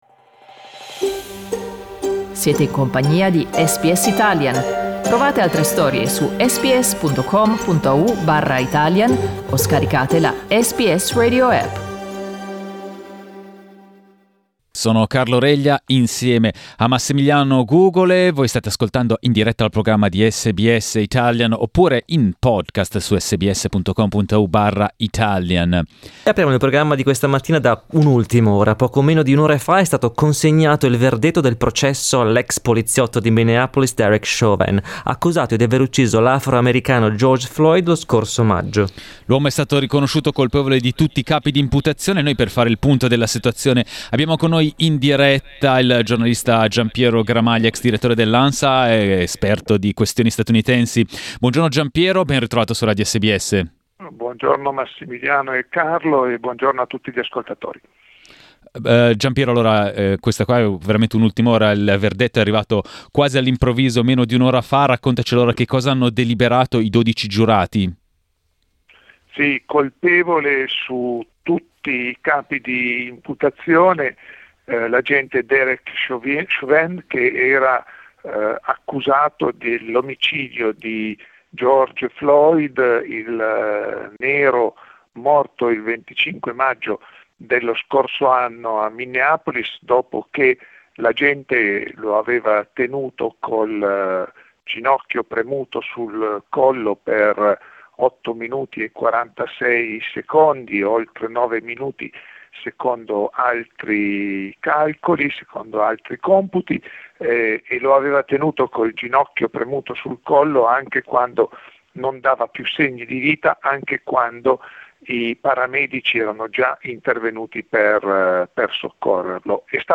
Poco dopo l'annuncio del verdetto, ne abbiamo parlato in diretta con il giornalista